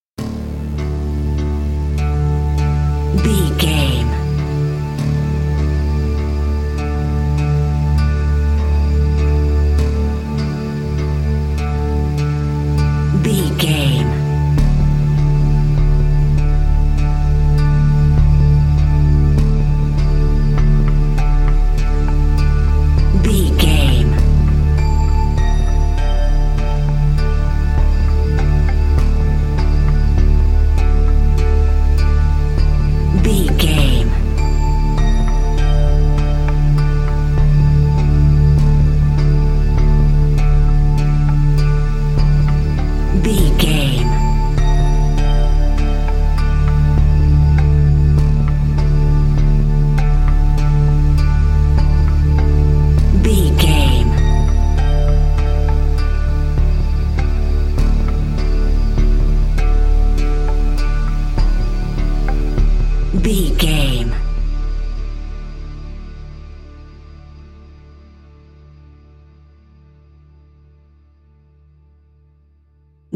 Aeolian/Minor
D
DOES THIS CLIP CONTAINS LYRICS OR HUMAN VOICE?
Slow
synthesiser
cello
electric piano
ominous
dark
haunting
tense
creepy